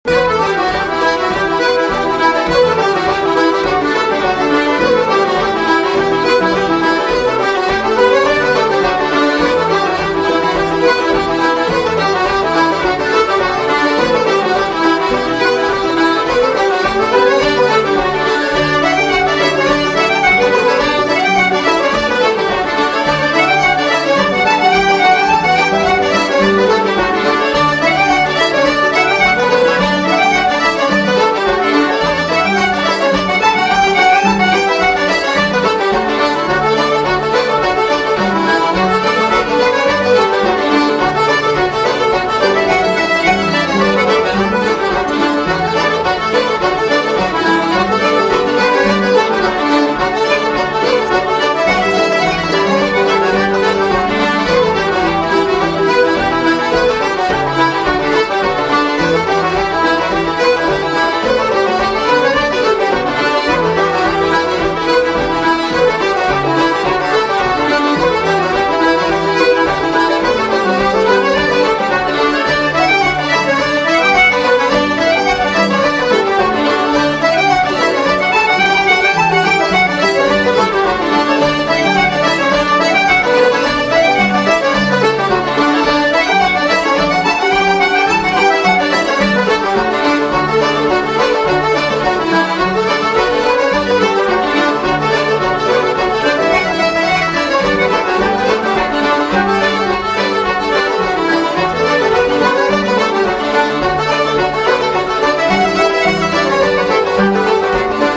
Irish traditional music